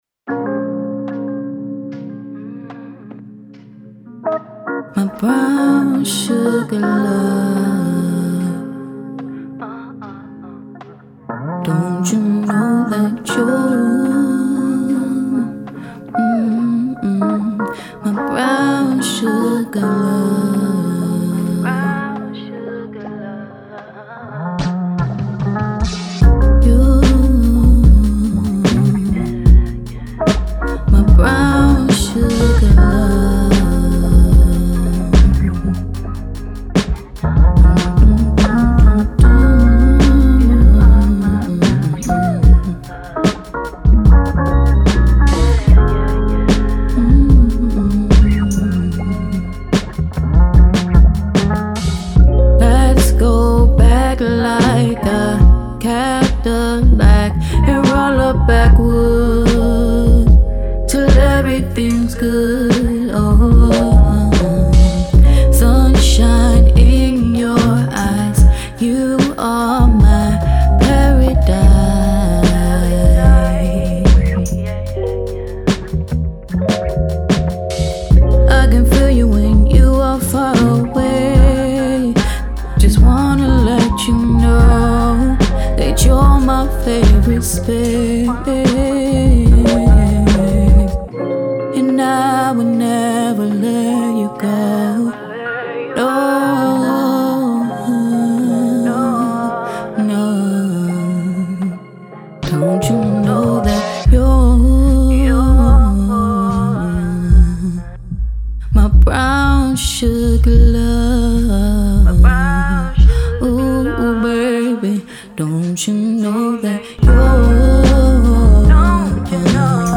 RnB
Description : Very soulful song for black love